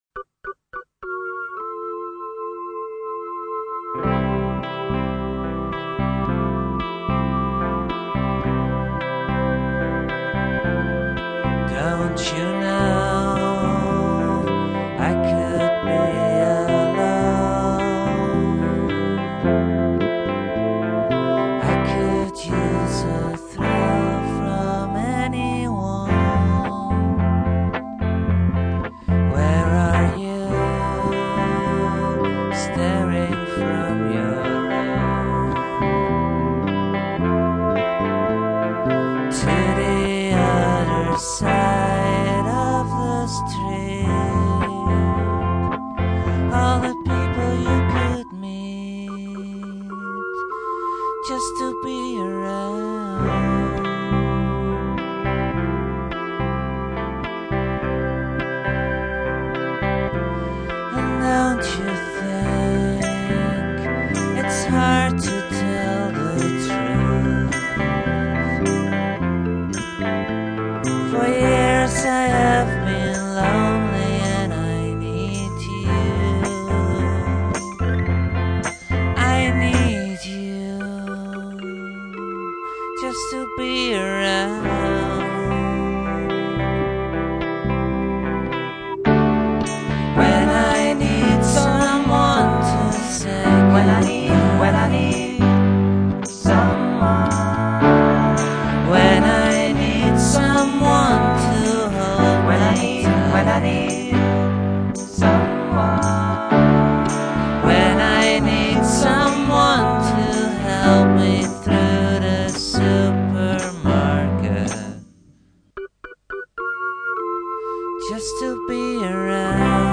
trivia: real beauty on a real piano in the bridge